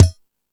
Lotsa Kicks(47).wav